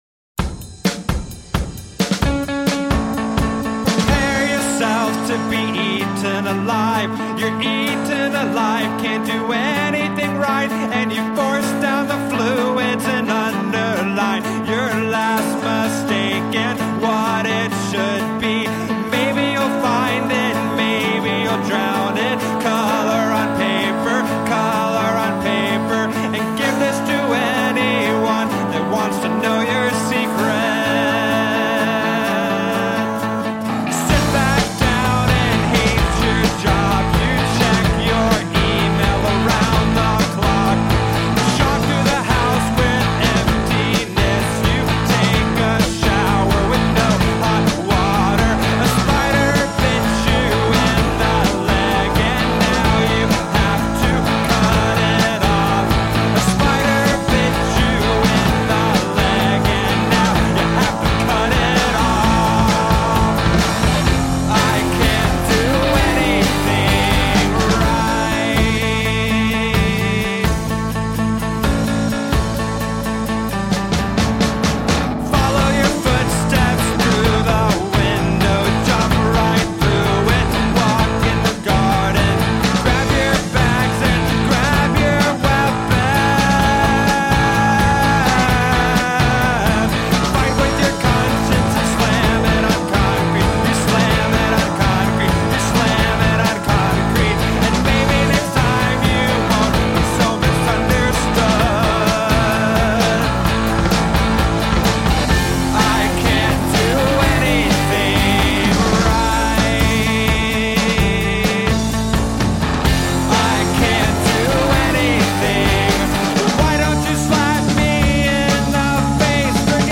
Tagged as: Alt Rock, Rock